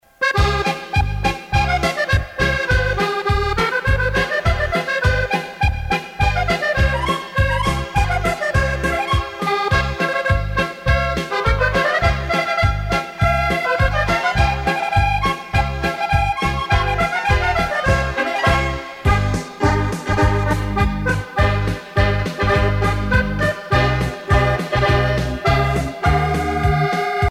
danse : quadrille
Pièce musicale éditée